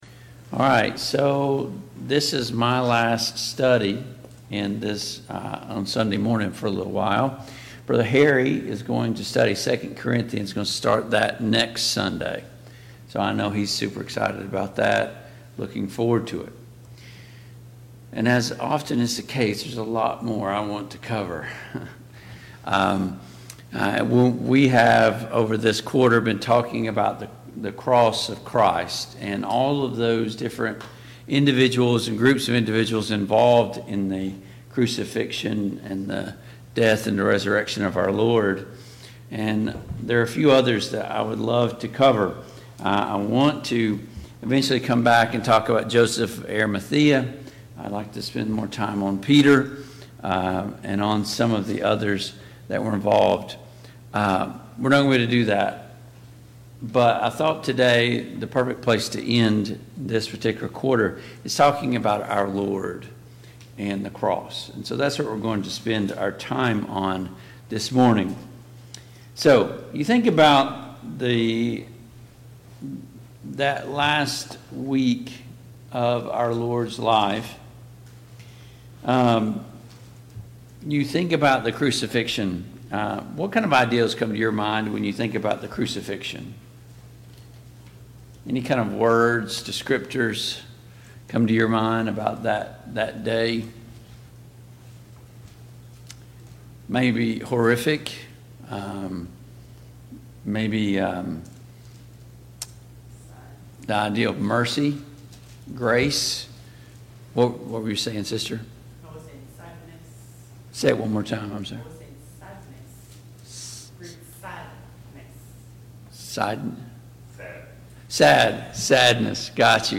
The Cast of the Cross Service Type: Sunday Morning Bible Class Topics: Jesus Christ , The Crucifixion « 6.